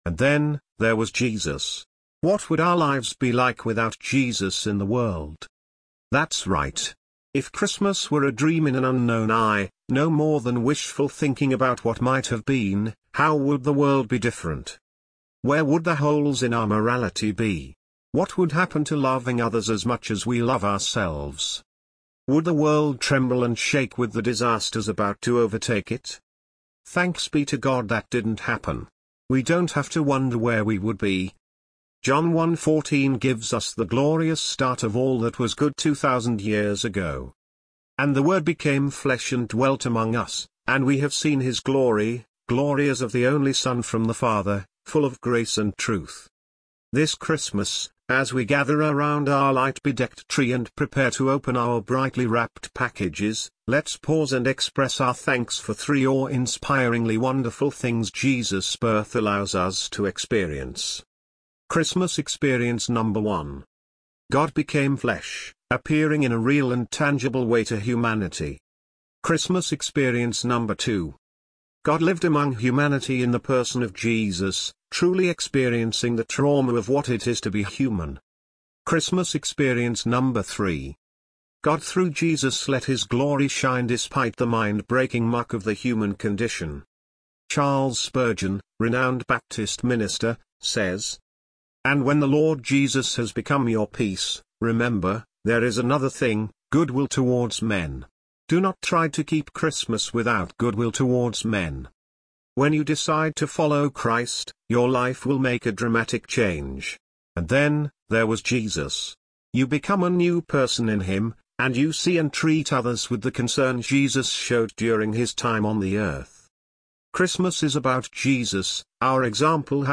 English Audio Version